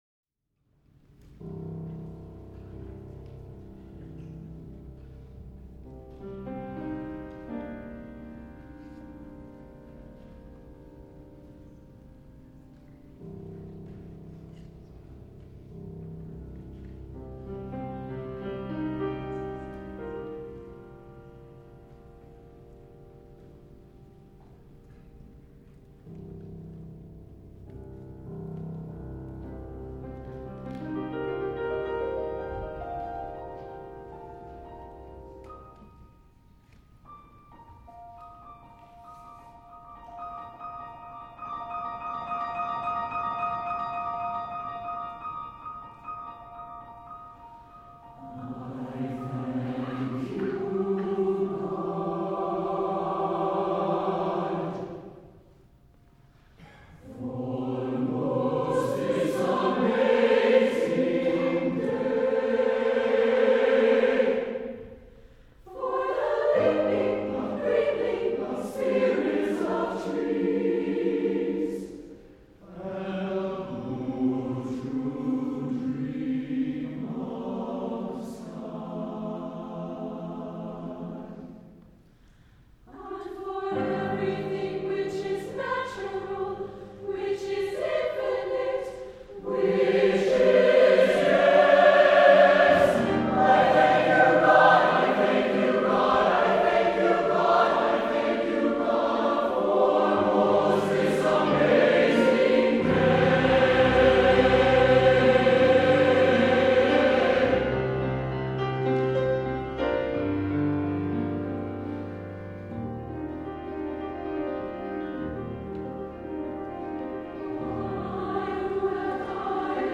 SSATB and piano